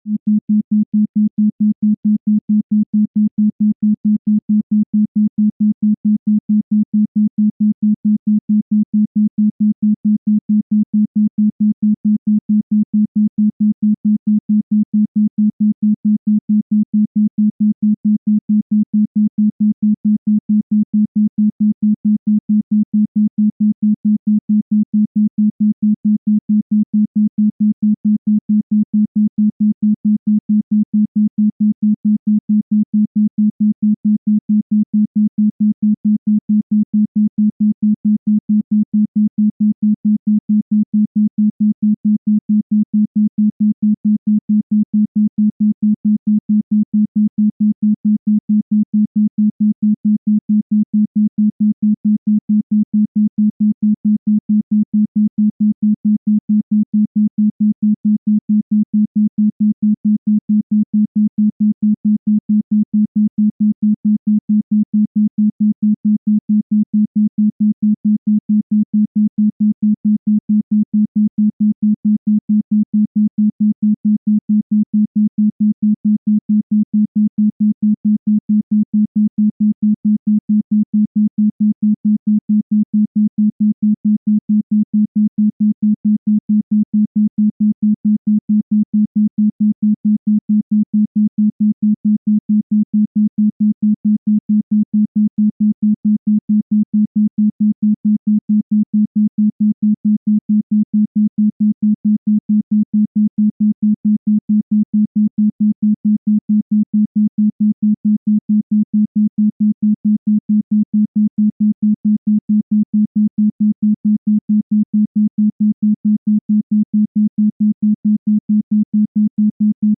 The frequency of 4.5 Hz sound effects free download
The frequency of 4.5 Hz (isochronic tone) corresponds to the shamanic state of consciousness. You can fall into a trance particularly well with this frequency and connect with the spiritual realm.